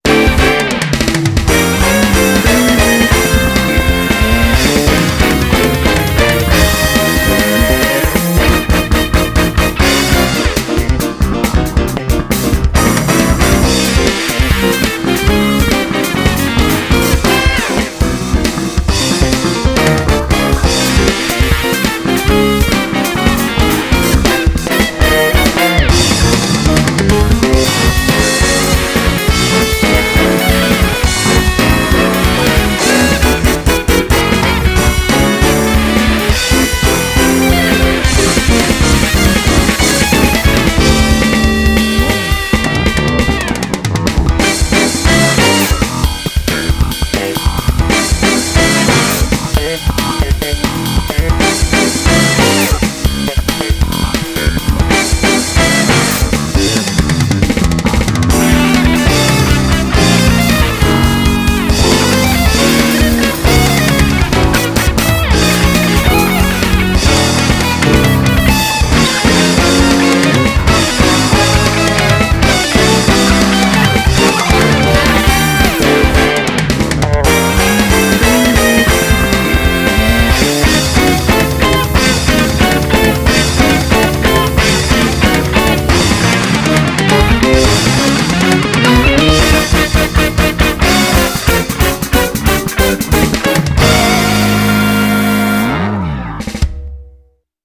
BPM137
Audio QualityPerfect (High Quality)
jazzy fresh tune!